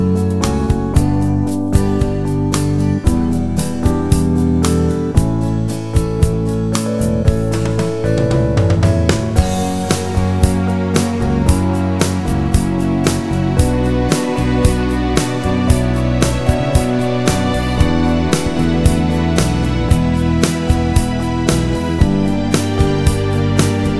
One Semitone Up Pop (1960s) 2:03 Buy £1.50